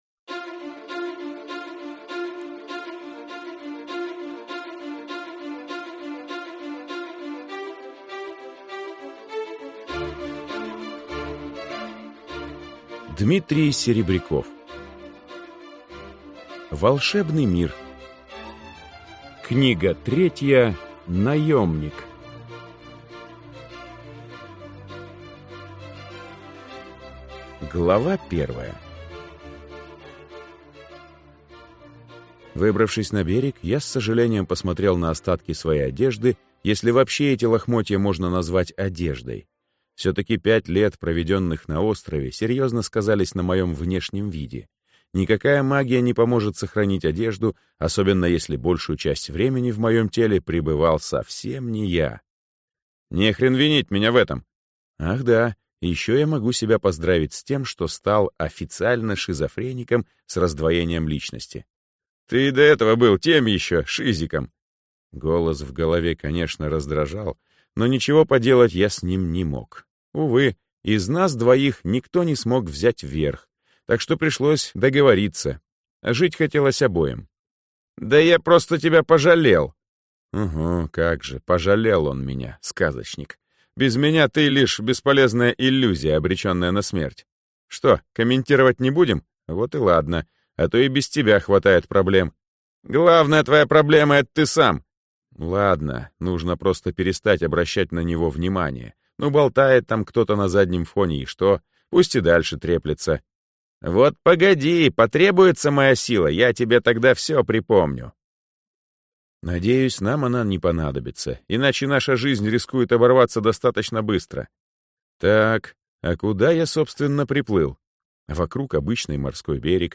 Аудиокнига Волшебный мир 3. Наёмник | Библиотека аудиокниг